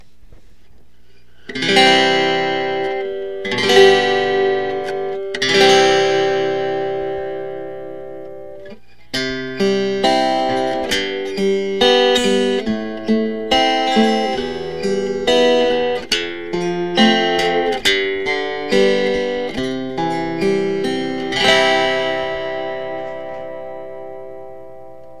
386煙AMP　アコギピックアップ研究編　貼り位置による差
貼り付け、PCで録音